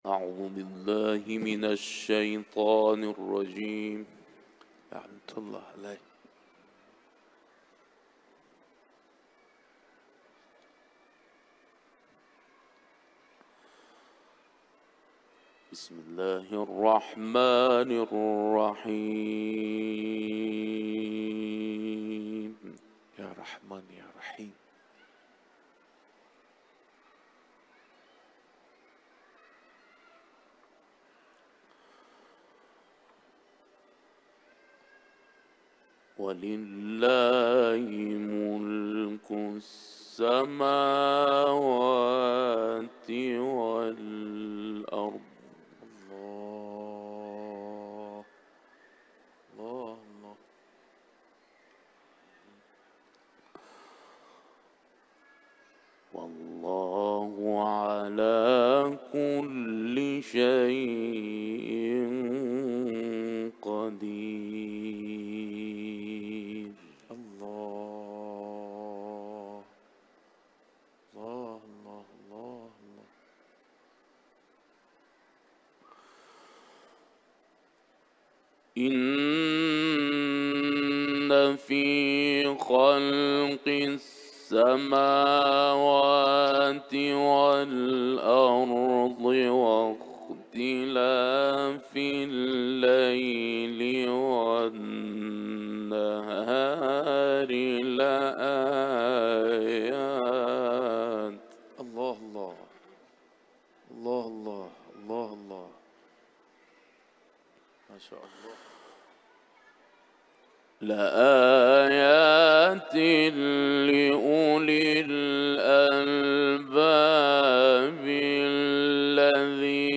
iranlı kâri